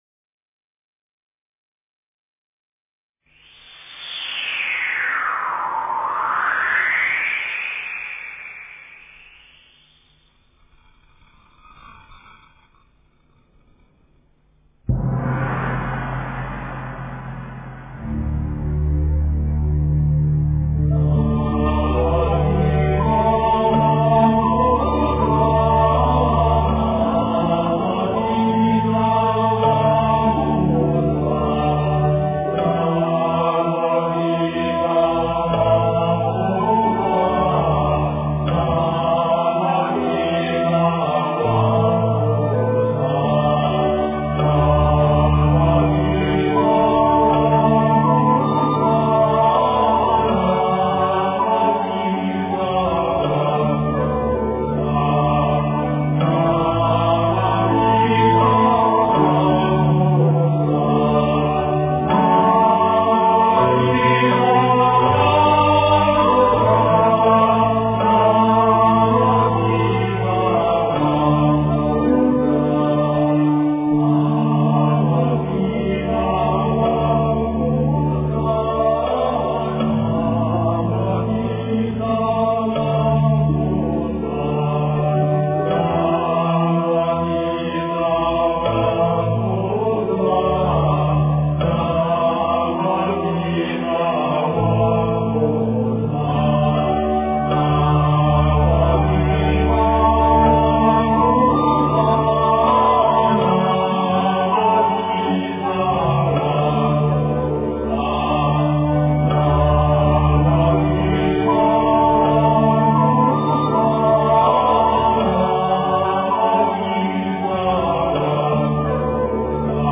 南无地藏王菩萨圣号--中国佛学院法师
南无地藏王菩萨圣号--中国佛学院法师 经忏 南无地藏王菩萨圣号--中国佛学院法师 点我： 标签: 佛音 经忏 佛教音乐 返回列表 上一篇： 净土文--佛教唱颂编 下一篇： 大佛母孔雀明王菩萨圣号-快版--新韵传音 相关文章 貧僧有話28說：我解决困难的方法--释星云 貧僧有話28說：我解决困难的方法--释星云...